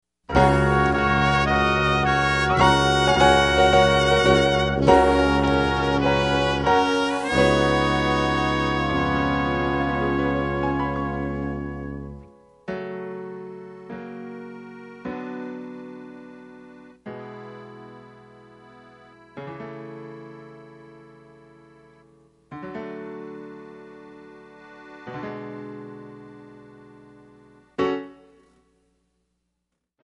Backing track files: Jazz/Big Band (222)